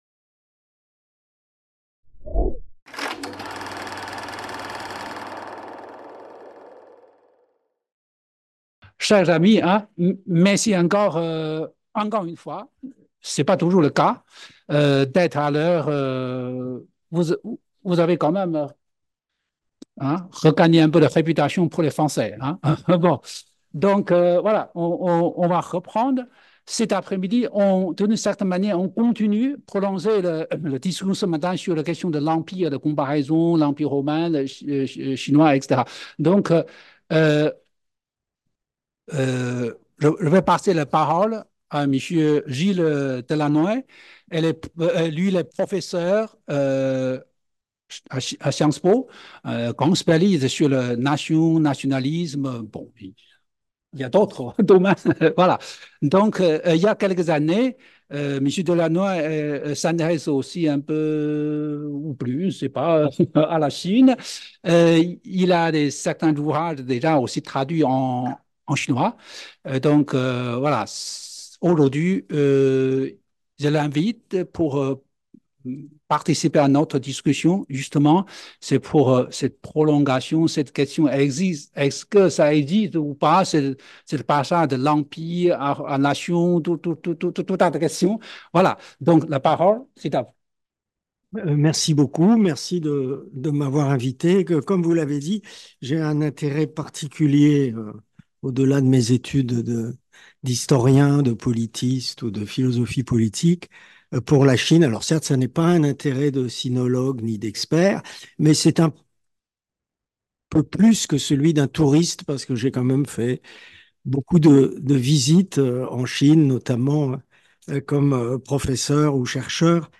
Première partie de l'après-midi de la table ronde autour de l'ouvrage "Qu'est ce que la Chine ?" de Zhaoguang Ge | Canal U